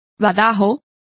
Complete with pronunciation of the translation of tongue.